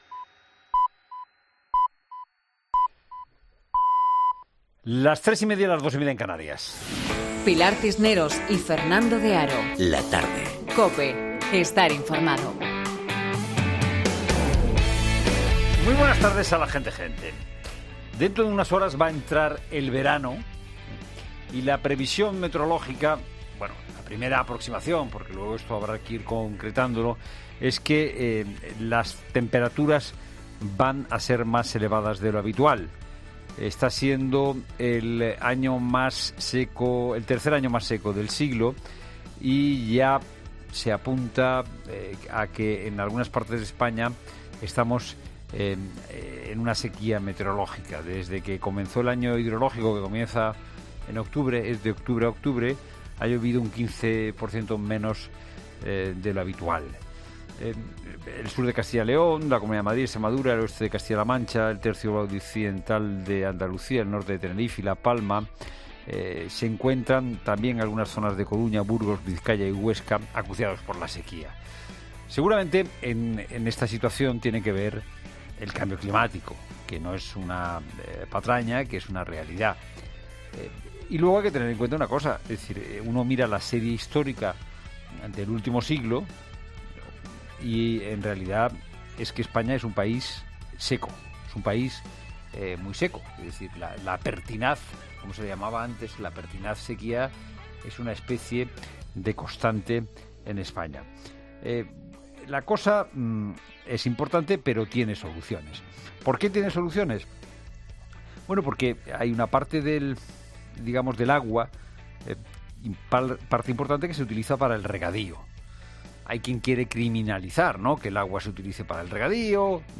es un magazine de tarde que se emite en COPE, de lunes a viernes, de 15 a 19 horas.